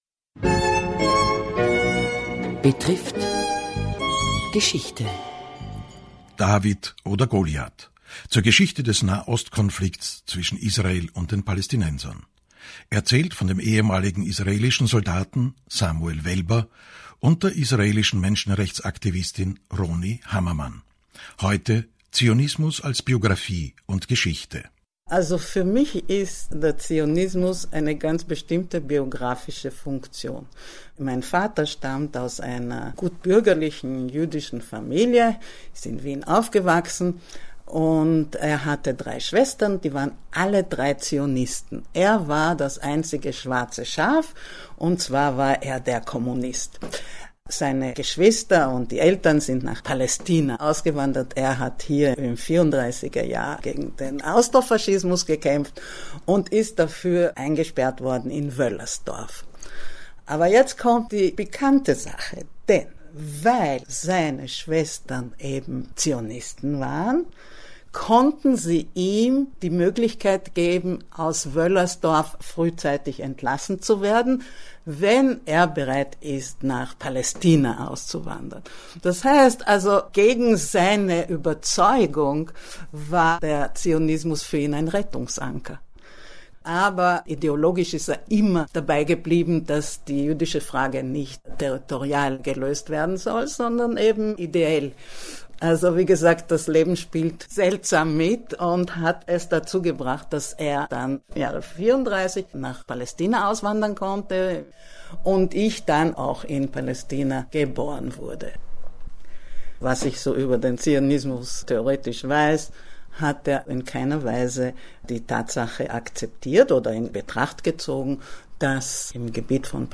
Eine Sendung des ORF 1 vom 12. Juni 2015.